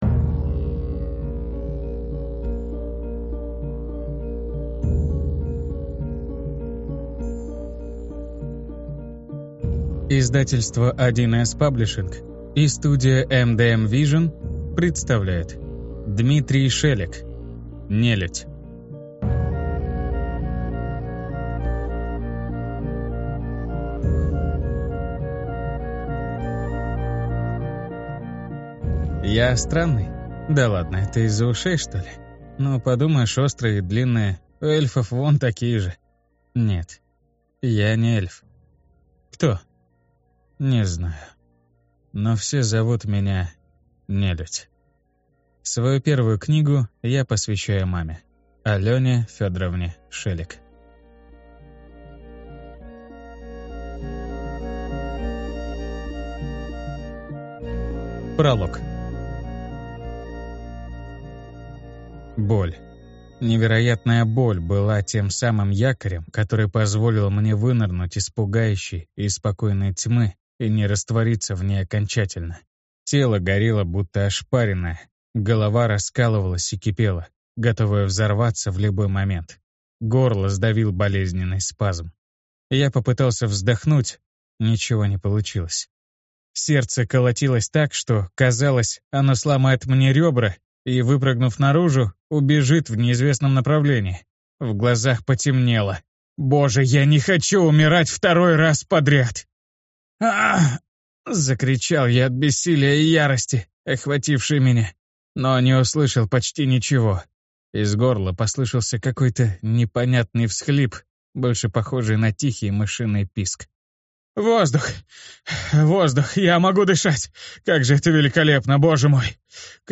Аудиокнига Нелюдь - купить, скачать и слушать онлайн | КнигоПоиск